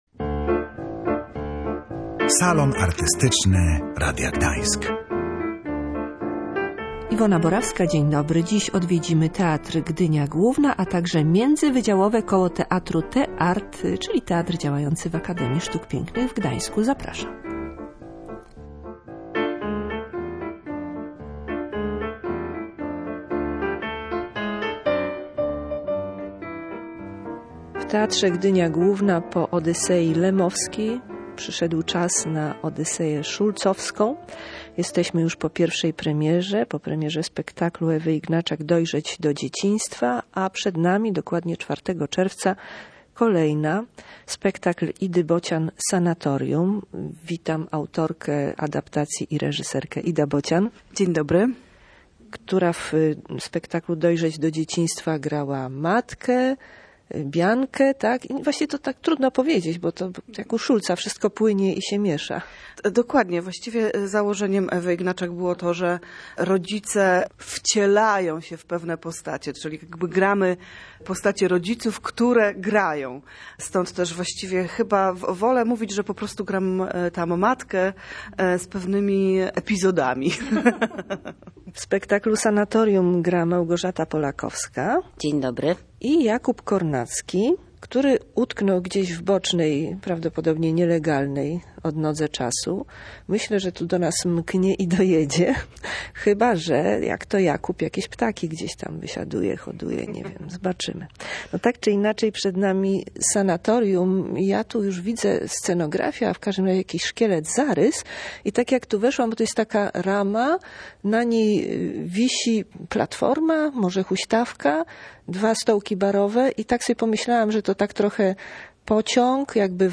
W "Salonie Artystycznym" Odyseja Schulzowska, rozmowa przed kolejną premierą - "Sanatorium".